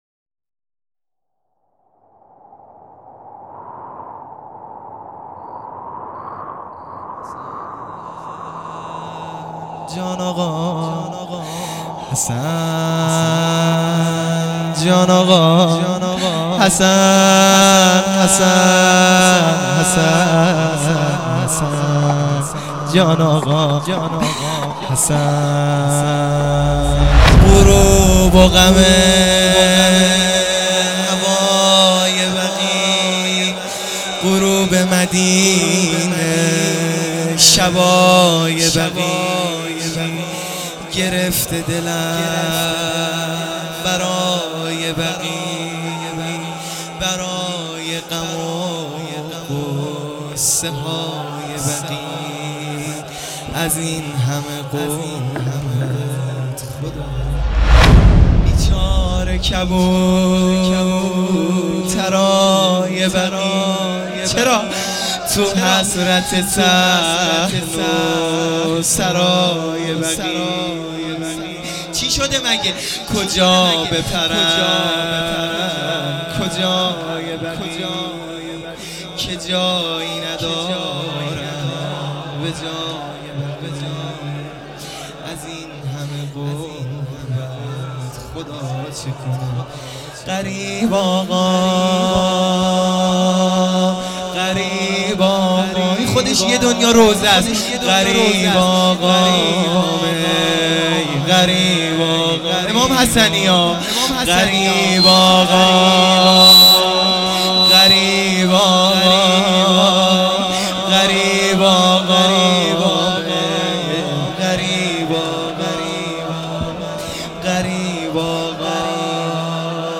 روضه | هوای بقیع